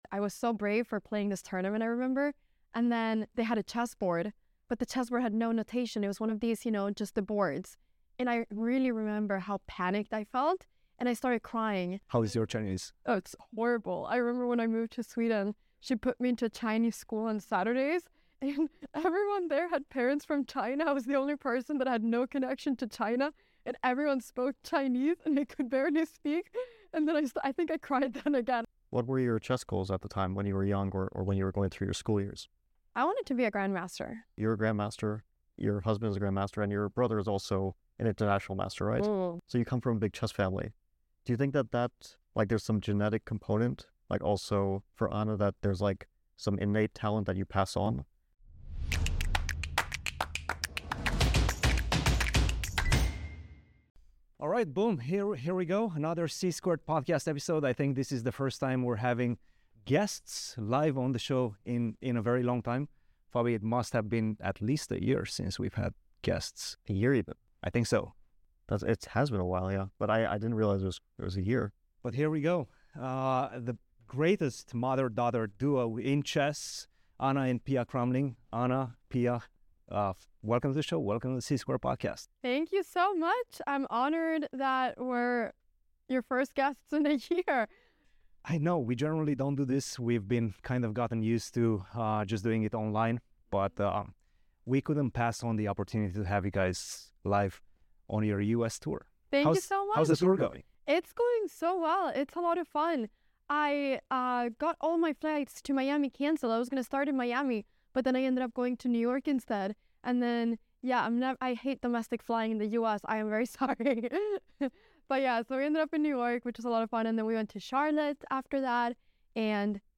1 Anna & Pia Cramling EXPOSE Chess Truths: Women's Titles Debate, Is Chess Talent Genetic? 57:18 Play Pause 4h ago 57:18 Play Pause Play later Play later Lists Like Liked 57:18 Anna & Pia Cramling, the greatest mother-daughter duo in chess, join the boys in St. Louis for a rare In-Person episode. We talk everything, from growing up in a chess family dynamic, to social media fame, to women's titles in chess and more.